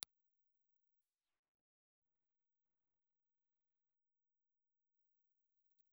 Ribbon
Impulse Response file of the Turner 87 ribbon microphone.
Turner_87_IR.aiff